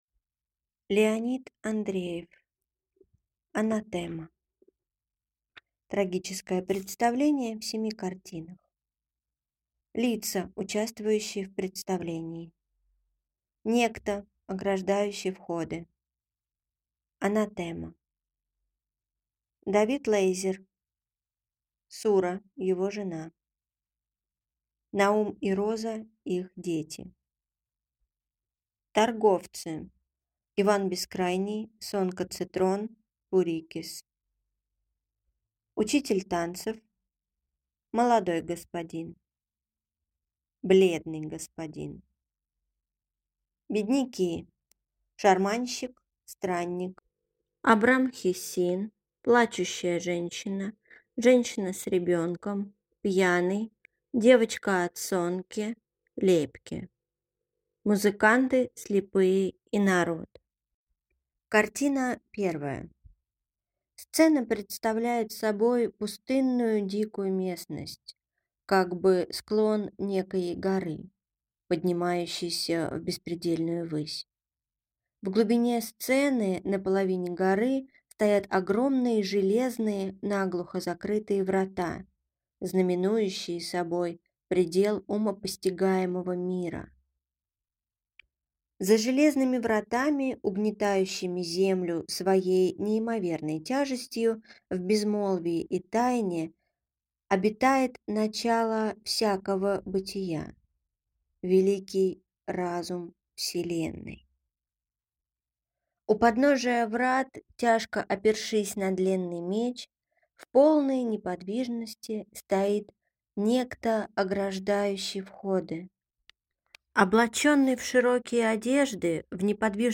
Аудиокнига Анатэма | Библиотека аудиокниг